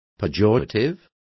Also find out how peyorativo is pronounced correctly.